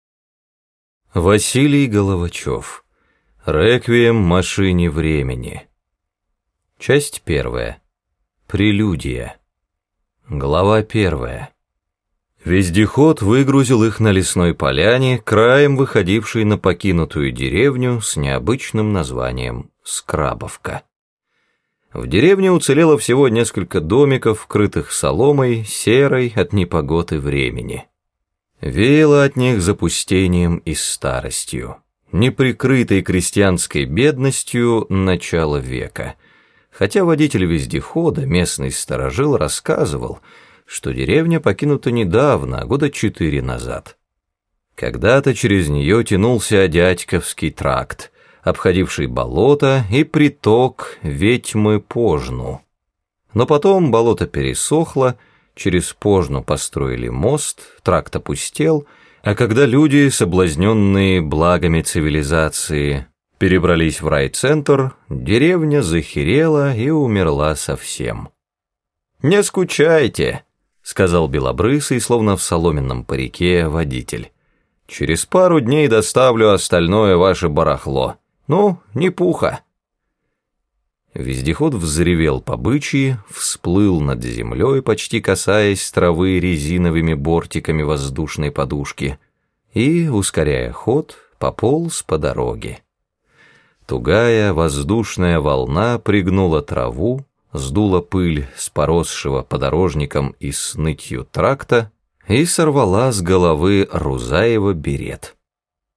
Раздел: Аудиокниги